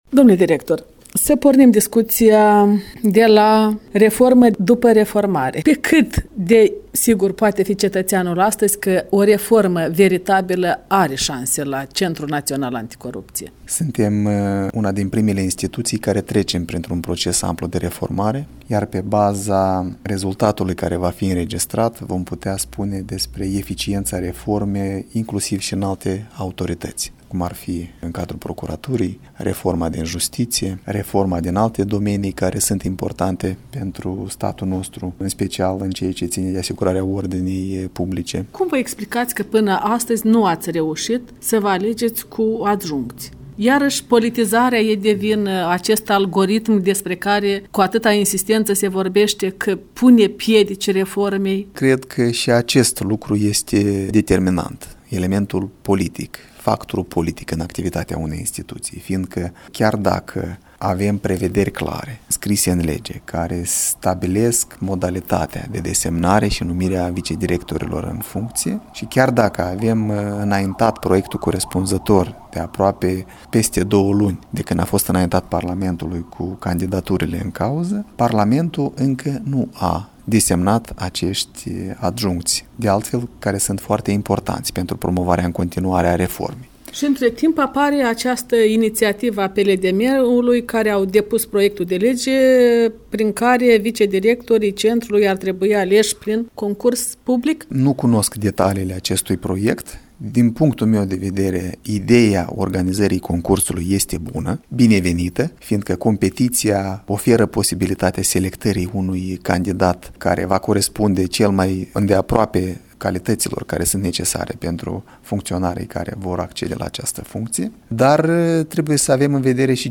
Un interviu cu Viorel Chetraru, directorul CNA